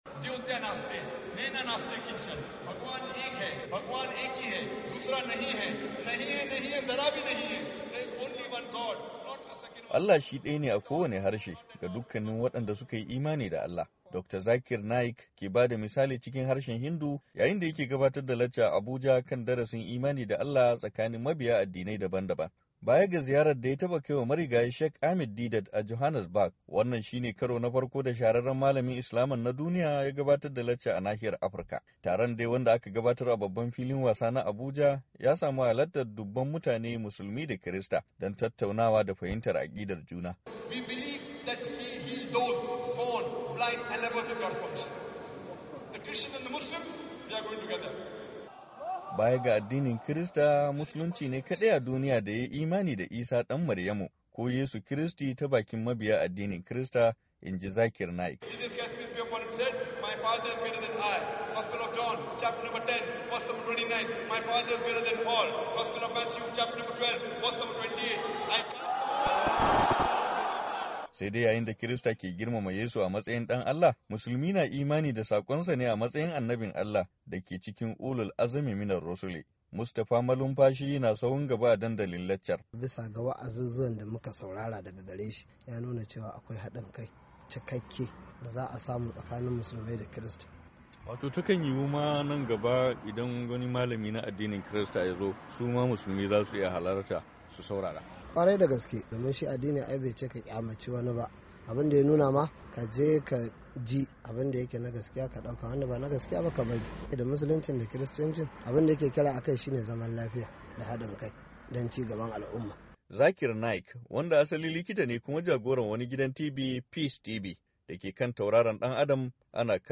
Fitaccen Malamin Islama Dr. Zakir Naik, ya gabatar da wata lacca a Abuja kan matsayar Allah cikin addinai daban daban na duniya.
Abuja Islamic lecture - 2:24